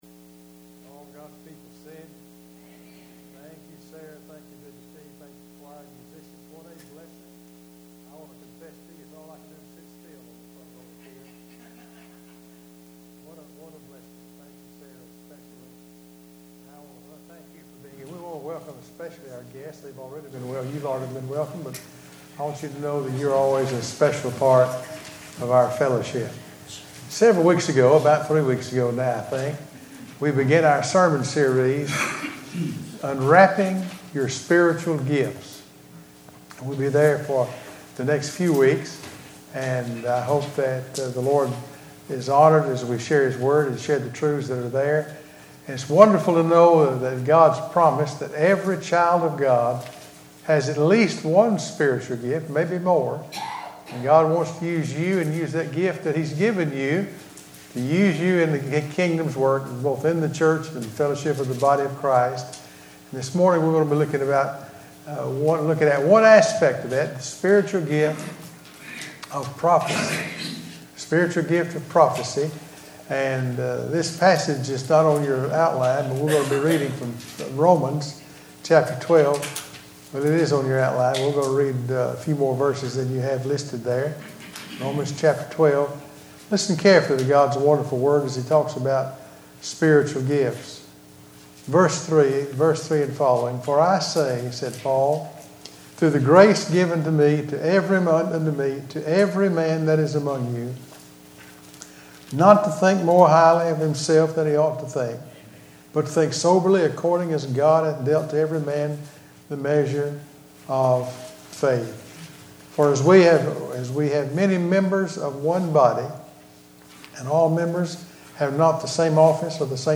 Sermons - Calvary Baptist Church